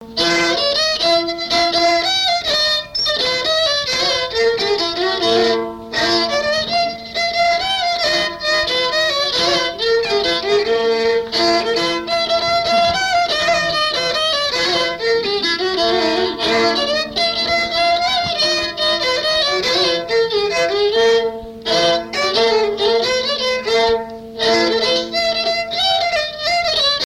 circonstance : fiançaille, noce
Pièce musicale inédite